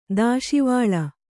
♪ dāśivāḷa